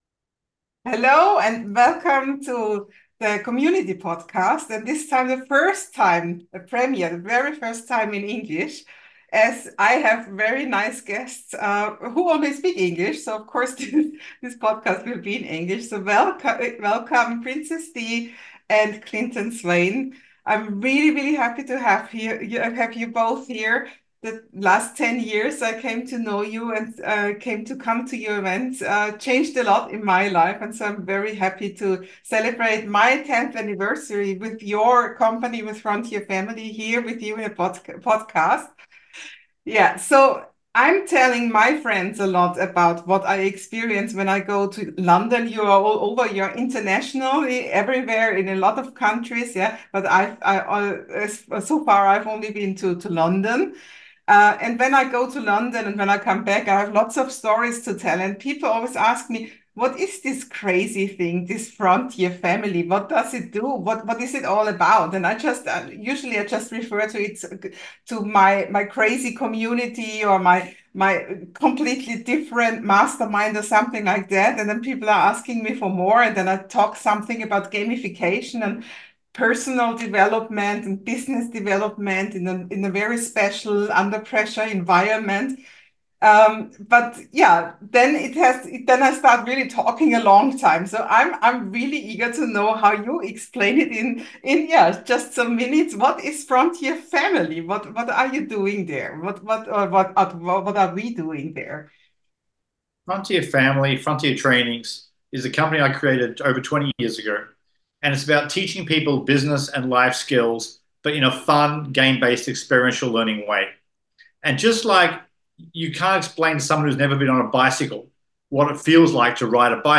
High time to have an interview with them and have them explain to you what thi is all about!